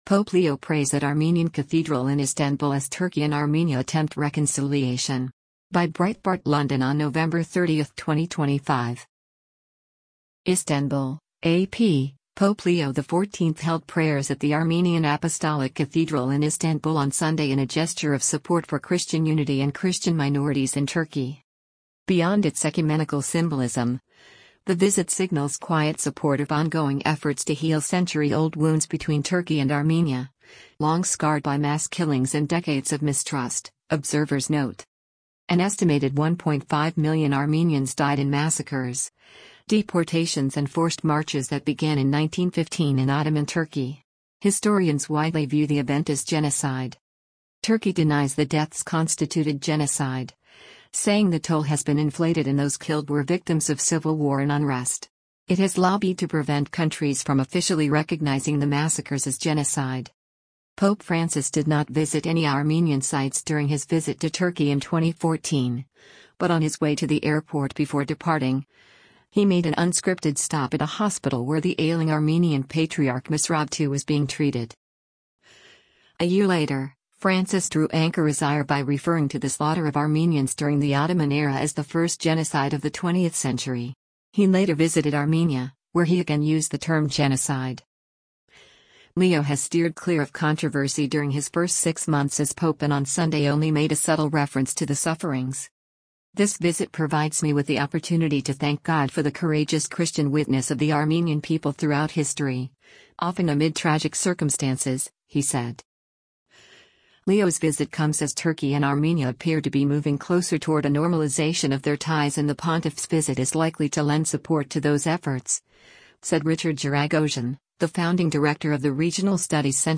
Leo and Istanbul-based Patriarch Sahak II Mashalian processed into the Holy Mother of God Patriarchal Church in a cloud of incense as a male choir chanted for a service steeped in the church´s traditions, with some 500 Armenian worshipers in attendance.